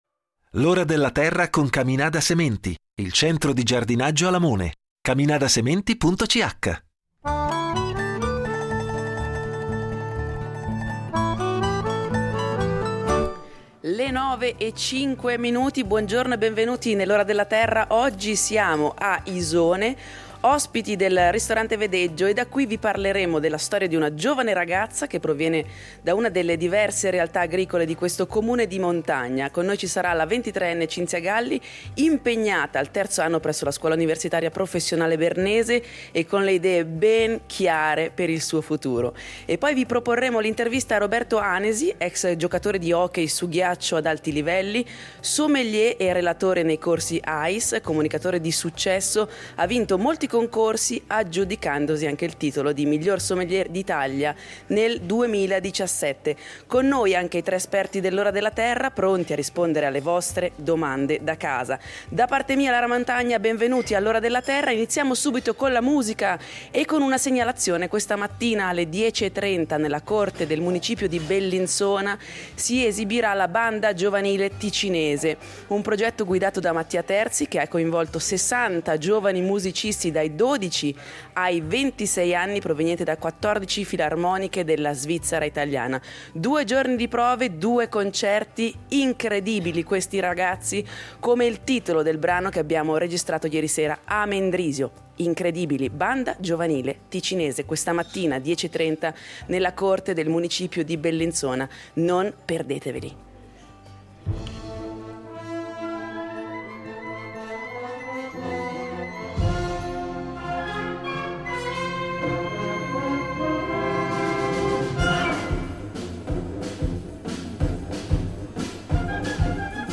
L’Ora della Terra sarà in diretta da Isone, ospiti del ristorante Vedeggio. Vi parleremo della storia di una giovane ragazza, che proviene da una delle diverse realtà agricole di questo comune di montagna.
Con noi anche i tre esperti de L’Ora della Terra, pronti a rispondere alle vostre domande da casa.